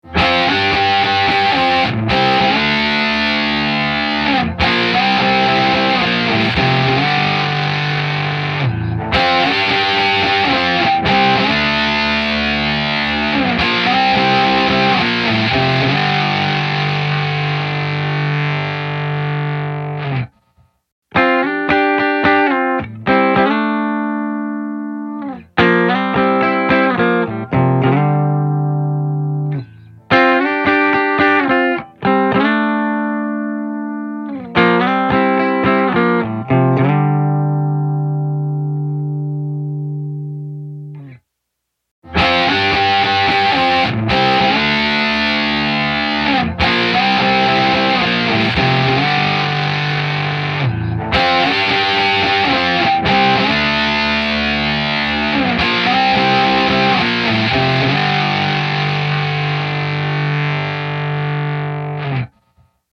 豊潤なリバーブと、引き裂くようなディストーション
MangledVerb | Electric Guitar | Preset: MangledVerb
MangledVerb-Electric-Guitar-Preset-MangledVerb.mp3